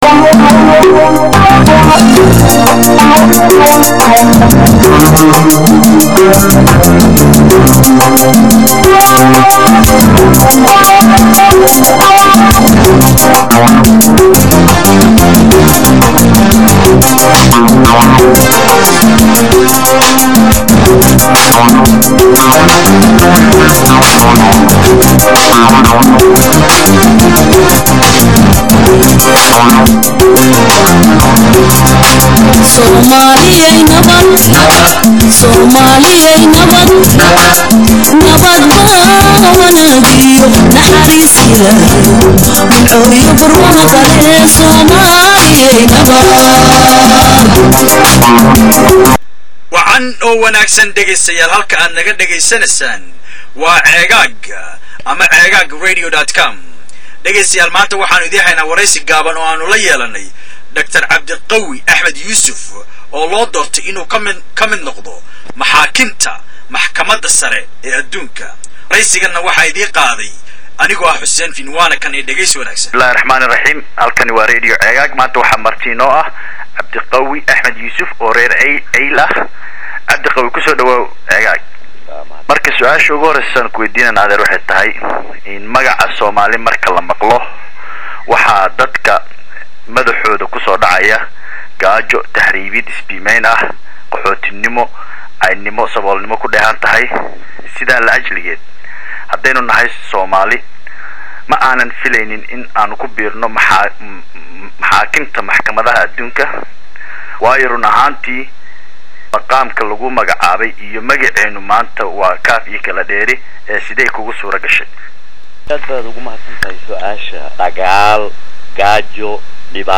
Ka raali ahaada Qadka Telefoonka oo hadalada yara goygoynaya
waraysigii Dr. Cabdiqawi.mp3